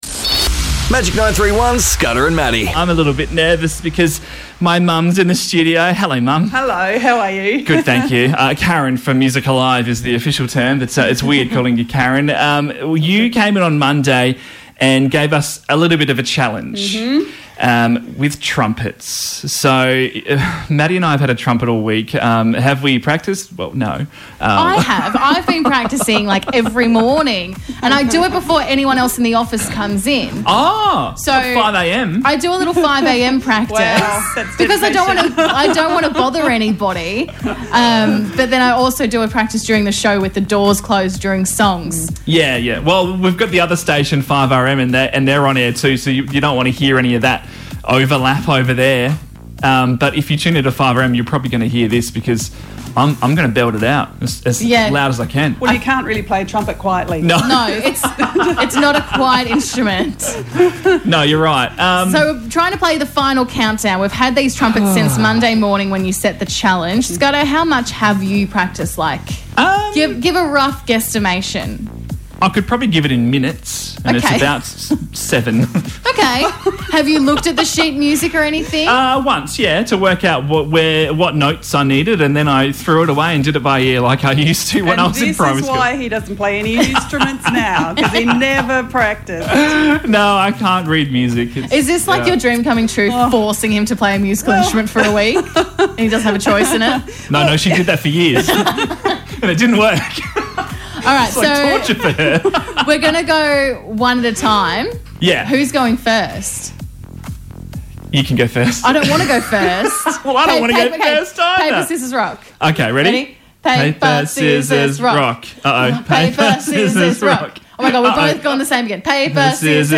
Our Trumpet recital!